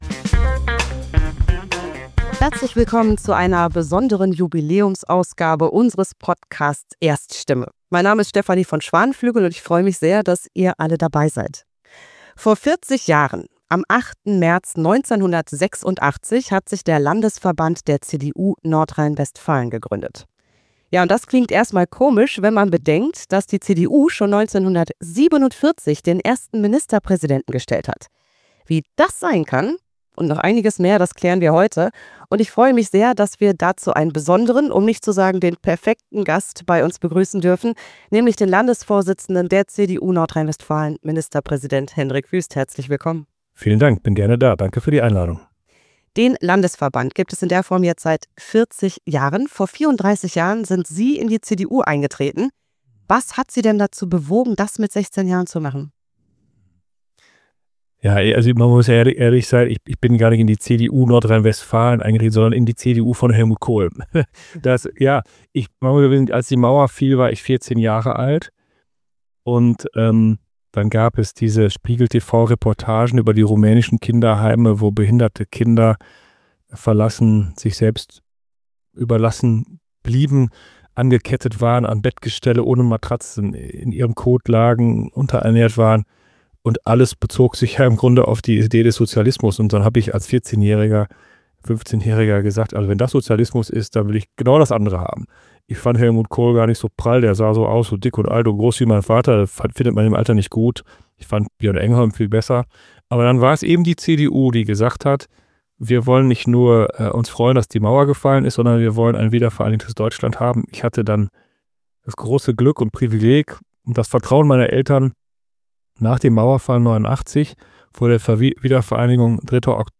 Wir freuen uns, mit dem Landesvorsitzenden Hendrik Wüst auf die Geschichte der CDU NRW zu blicken. Wir sprechen über Kontinuitätslinien, tragende Säulen und Grundsätze, aber auch Transformationen und Herausforderungen.